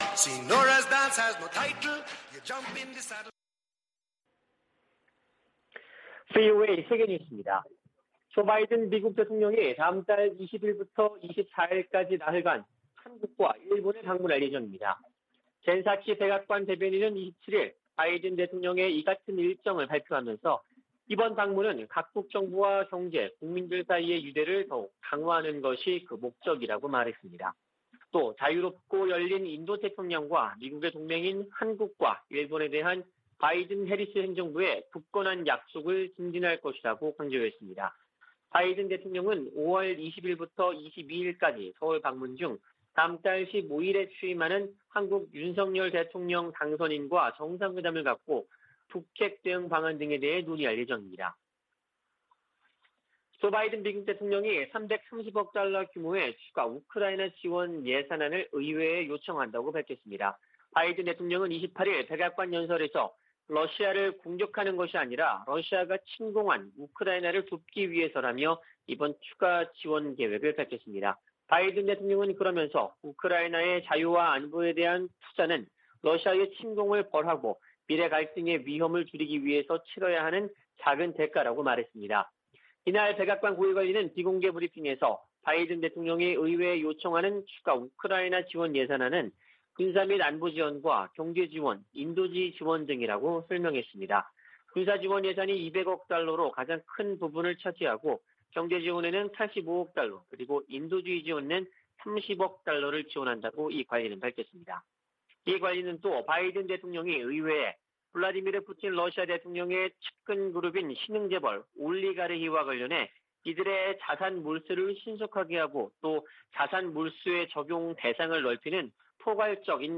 VOA 한국어 아침 뉴스 프로그램 '워싱턴 뉴스 광장' 2022년 4월 29일 방송입니다. 조 바이든 대통령이 다음 달 20일부터 24일까지 한국과 일본을 방문합니다. 한국의 윤석열 대통령 당선인 측은 바이든 대통령 방한을 환영하면서 포괄적 전략동맹이 강화되는 계기가 될 것으로 기대했습니다. 미 하원 청문회에서 ‘파이브 아이즈’(Five Eyes) 정보 동맹을 한·일 등으로 확대하는 문제가 거론됐습니다.